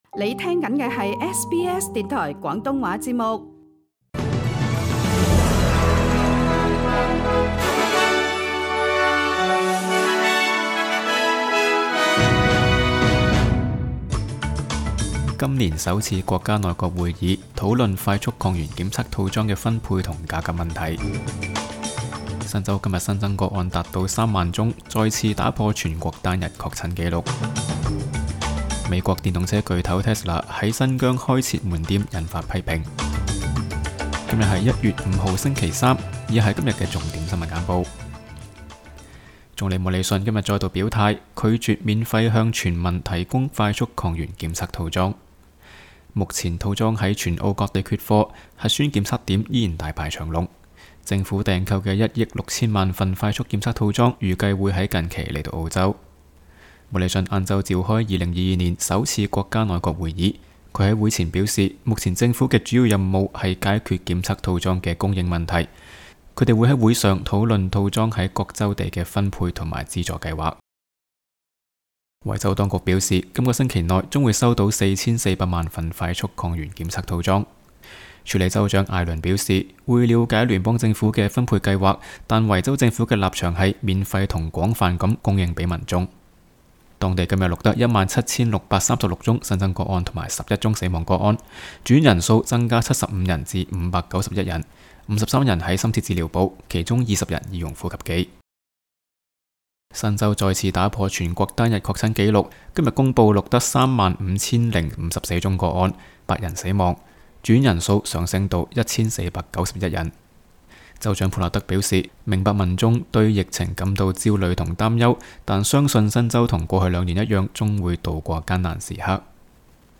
SBS 新闻简报（1月5日）